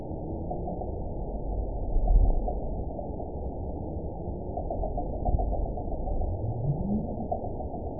event 916552 date 01/05/23 time 09:00:06 GMT (2 years, 4 months ago) score 9.03 location INACTIVE detected by nrw target species NRW annotations +NRW Spectrogram: Frequency (kHz) vs. Time (s) audio not available .wav